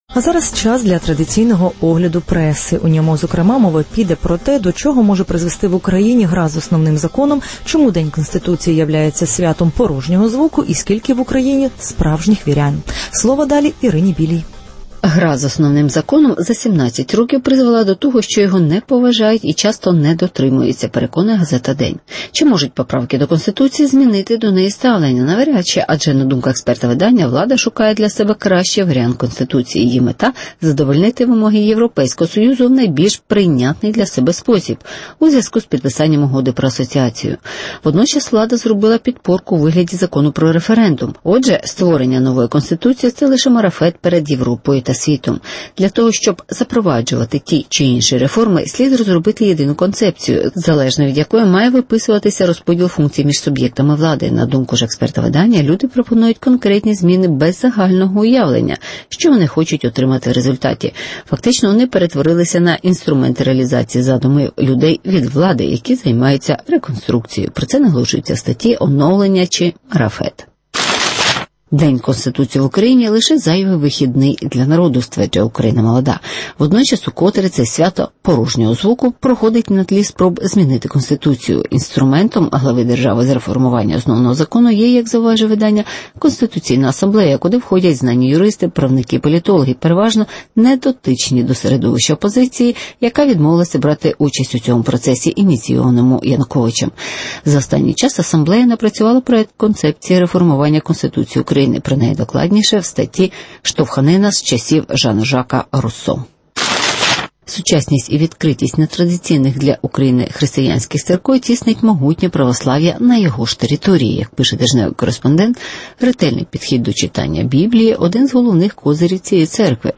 Чому Янукович грає з Конституцією? (Огляд преси)